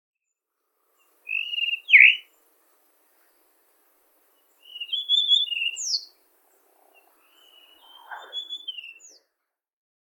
今回、私が録音した場所は大和葛城山へ向かう最寄り駅の奈良県御所（ごせ）駅前の大型スーパーである。
【録音①】　イソヒヨドリ（さえずり）　2024年5月
（鳴き声の特徴）ヒーチュリツチーチュルリルなど複雑な鳴声で囀る。